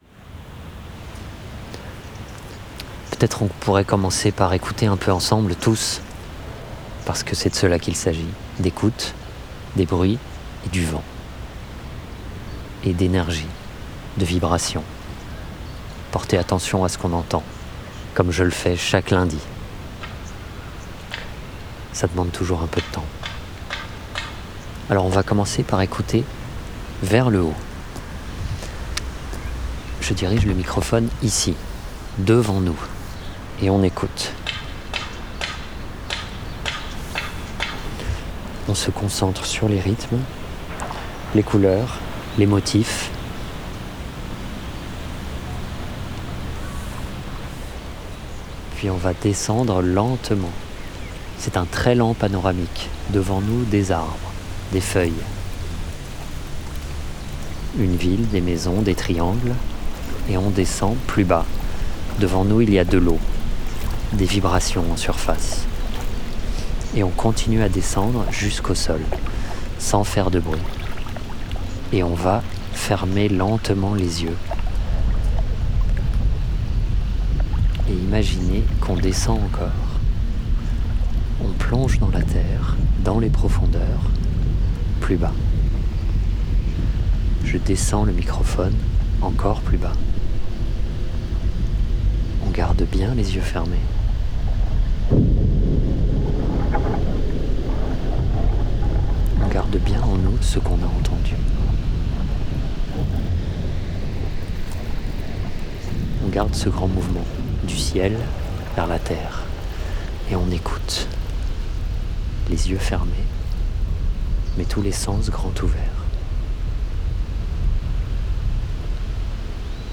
Blow Again, 2025 Projet en cours d’écriture Performance sonore en extérieur, parcs ou jardins, jauge publique 50 personnes Durée à préciser. Dispositif numérique et prise de son stéréophonique en direct, casques sans fils Silent Disco.
Blow Again est une fiction sonore en direct, en extérieur, que le public écoute au casque. Un preneur du son convoque le public pour retrouver les fragments d’une histoire passée, balayée par le vent.
Autour d’un enregistrement mystérieux, dans le souvenir d’un vieux film policier, on explore le paysage sonore, bercé par la poésie des éléments naturels.
Extrait audio de la performance :